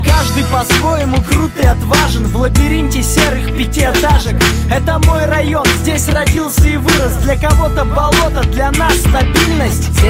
Отрывок из музыкальной заставки сериала